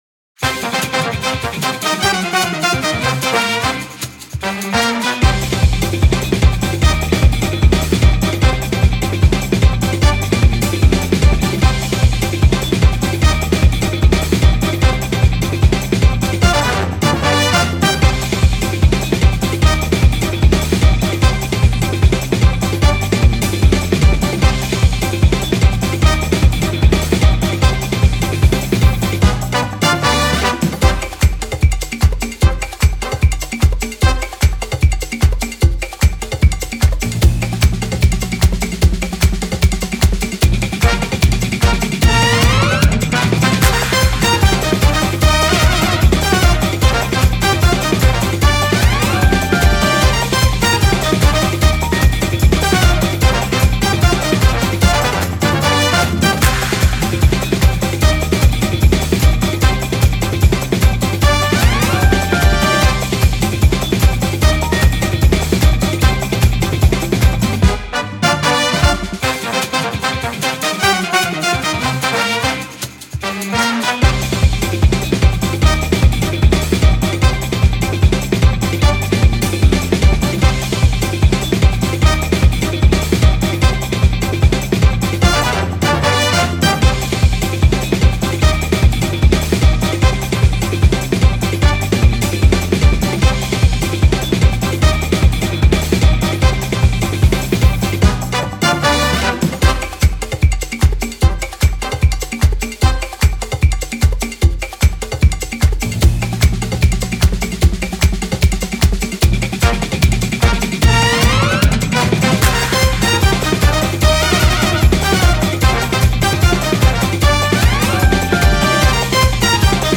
トランペットが主体の明るくて元気なBGM。